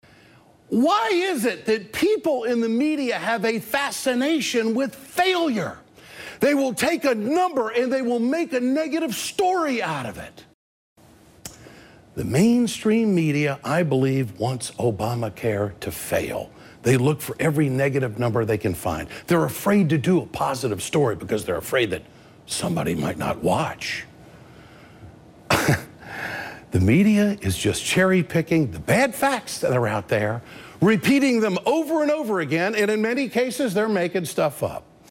Liberal MSNBC anchor Ed Schultz on Tuesday seriously proclaimed, "The mainstream media, I believe, wants ObamaCare to fail.
The angry host sputtered, "Why is it that people in the media have a fascination with failure?